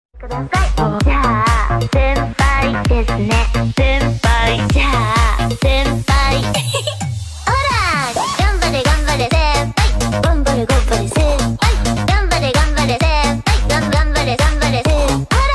ungi Meme Sound Effect
Category: TikTok Soundboard